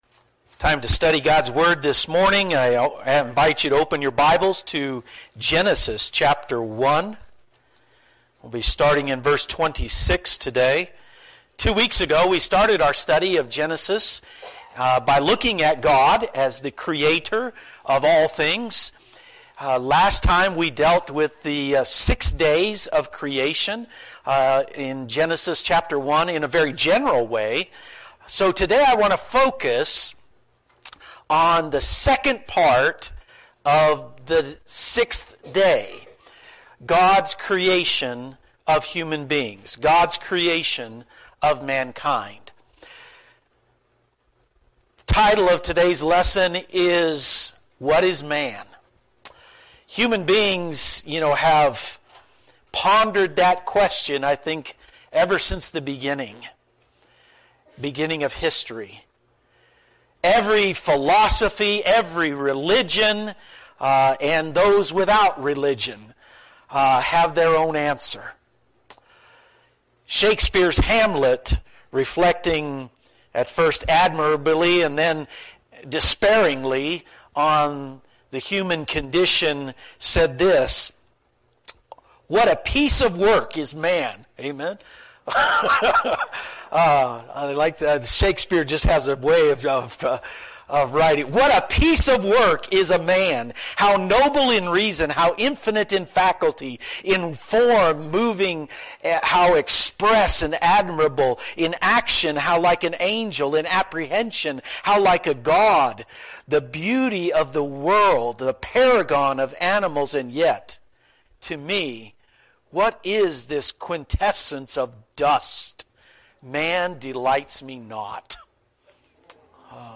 Lesson 3.